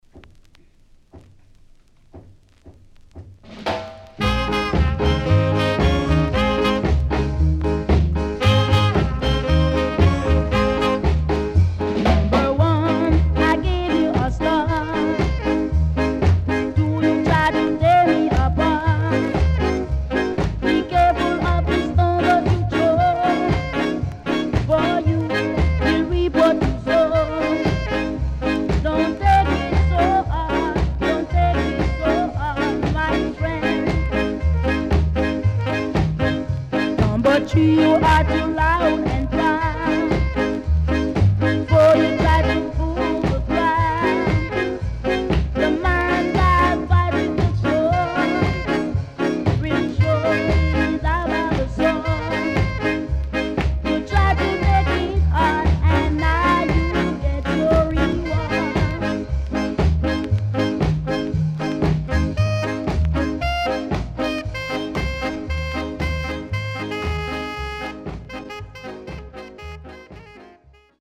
Good Ska Vocal
SIDE A:所々チリノイズがあり、少しプチパチノイズ入ります。